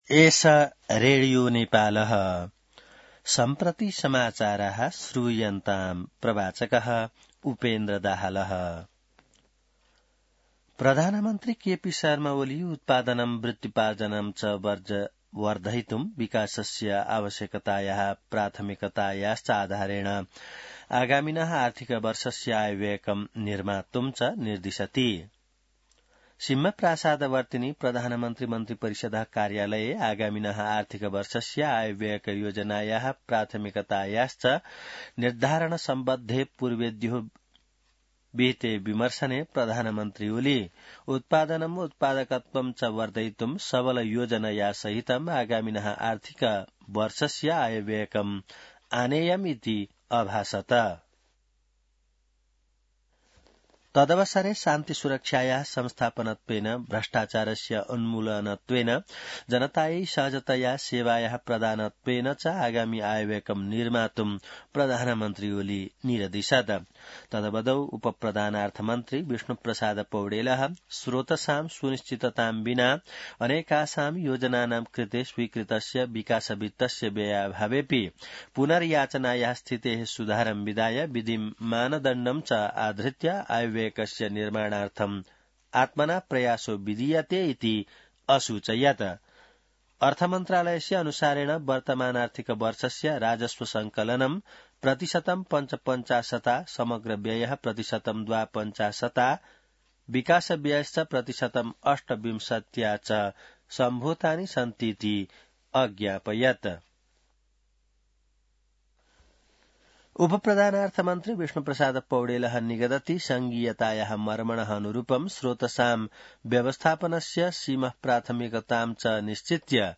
संस्कृत समाचार : २९ चैत , २०८१